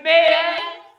13 Boiling In Dust Vox Meeting.wav